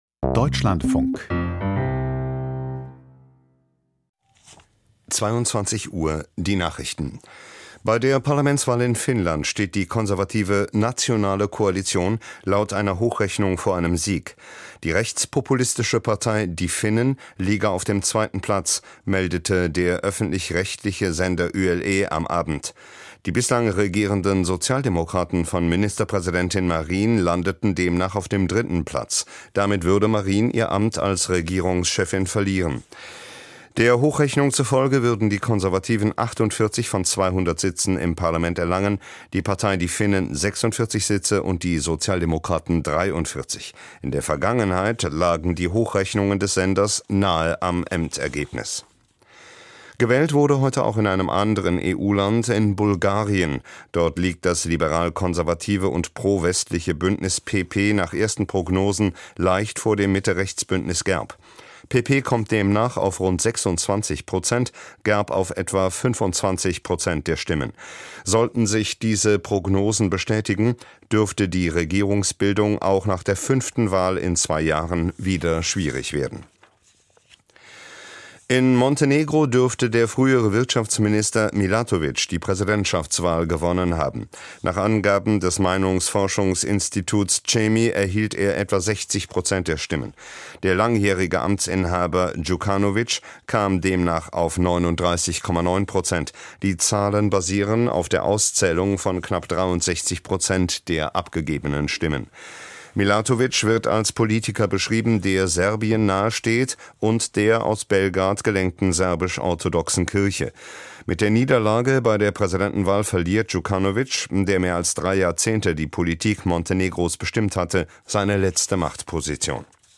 Nachrichten vom 02.04.2023, 22:00 Uhr